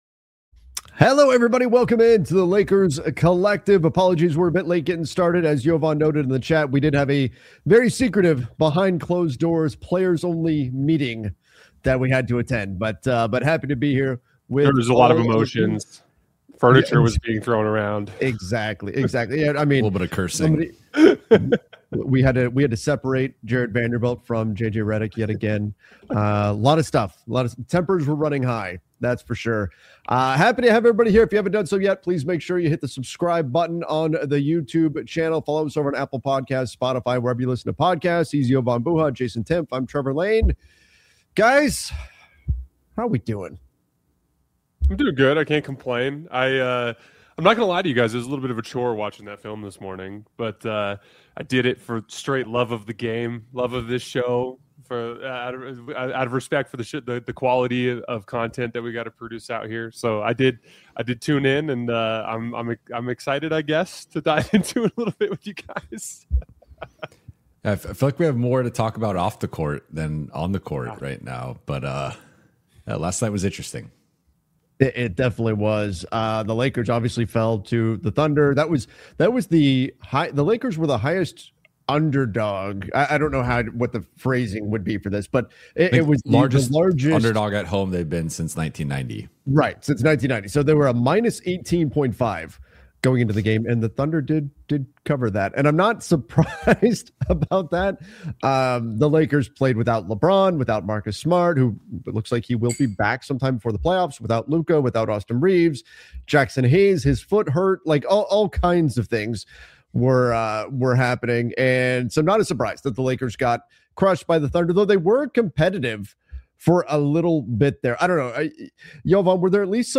We go live every Thursday at 10:00 AM PT with sharp analysis, smart X’s-and-O’s talk, and honest conversations about all things Lakers — from game breakdowns and player development to cap moves and playoff paths.
a weekly Los Angeles Lakers roundtable